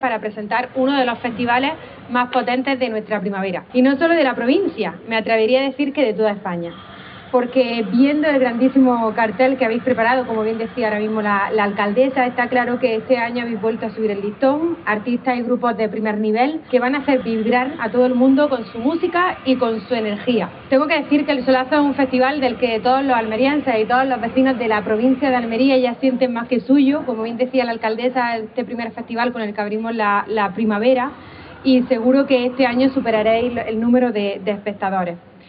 Los aledaños del Teatro Cervantes, junto a la gran pantalla, han sido el escenario de la presentación oficial de Solazo Fest 2025, reuniendo tanto al Ayuntamiento de Almería, que organizador a través del Área de Cultura, Tradiciones y Fiestas Mayores, como a Diputación Provincial a través de Costa de Almería, y Kuver Producciones como principal promotor, con el apoyo de numerosos patrocinadores, también presentes en el acto.
03-04_solazo_diputada.mp3